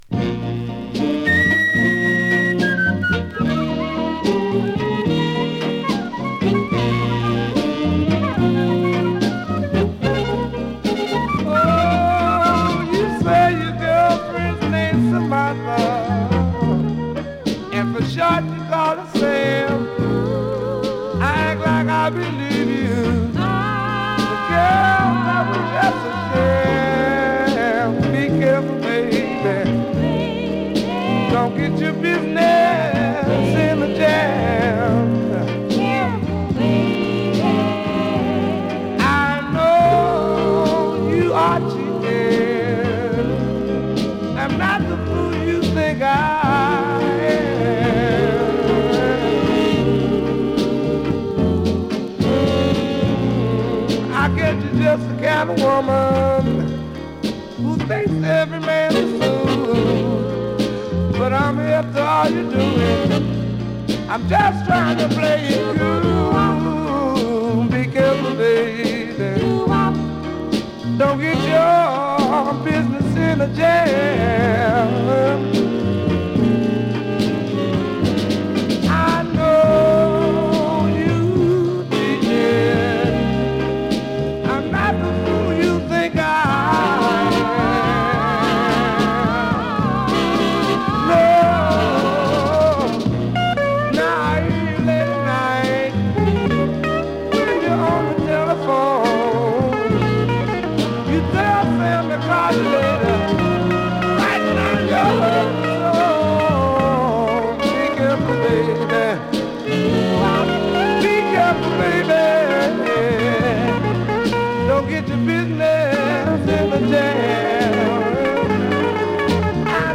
◆盤質Ａ面/VG+◆盤質Ｂ面/VG+音質は良好です。